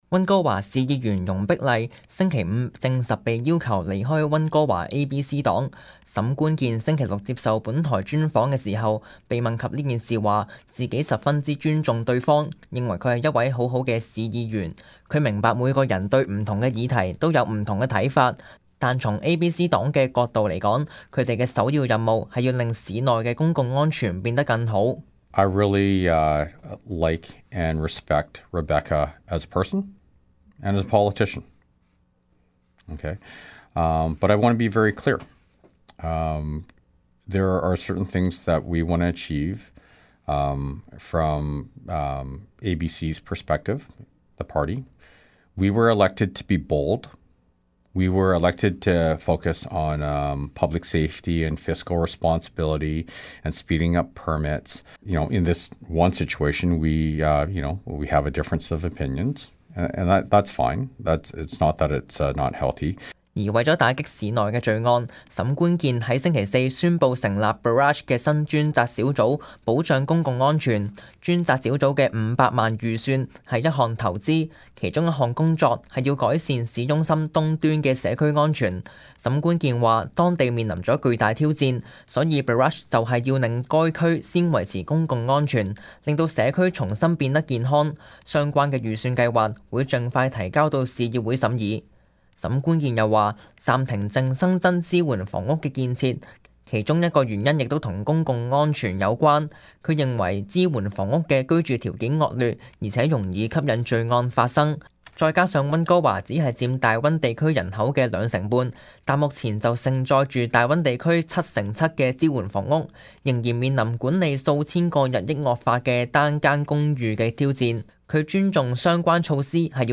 Local News 本地新聞
溫哥華市長沈觀健星期六接受本台專訪，談到最近有溫哥華市議員被要求離開ABC黨一事，亦有討論到加強溫哥華市公共安全及暫停淨新增支援房屋等多項議題。